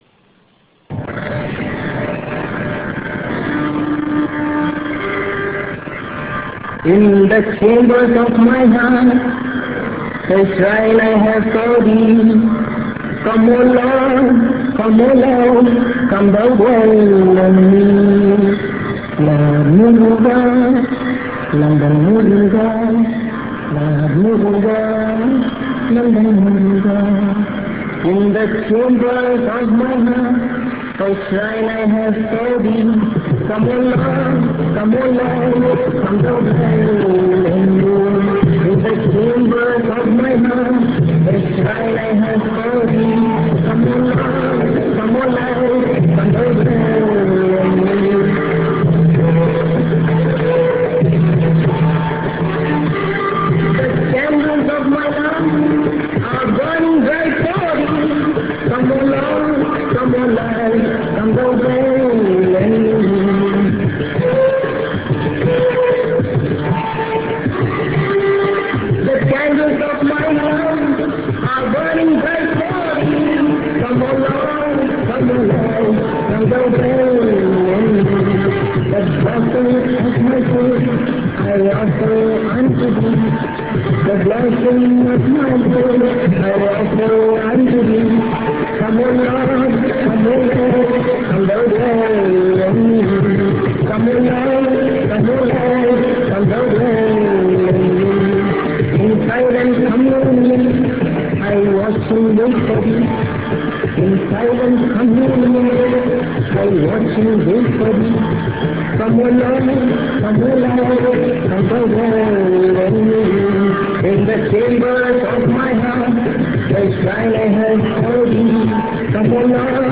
Recording not too clear on my real
> first visit to London (at Westminster Central Hall).